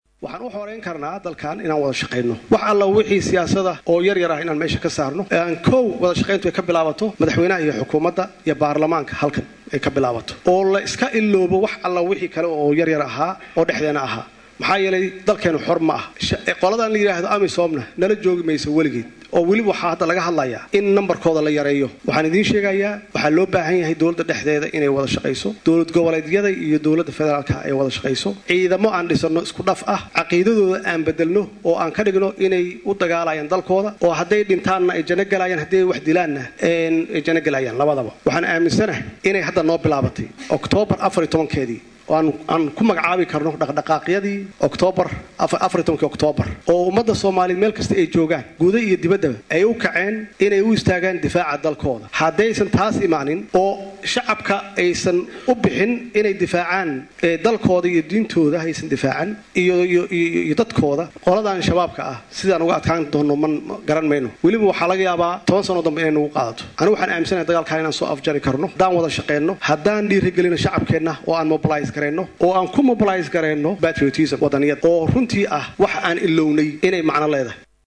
Hadalkan ayuu ka sheegay Maxamed C/laahi Maxamed (Farmaajo) Madaxweynaha Soomaaliya xilli uu xalay khudbad ka jeedinayay shirweynaha ay isgu yimaadeen Madaxda Dowladda dhexe iyo Dowlad Goboleedyada.